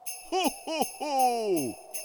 cuckoo-clock-01.wav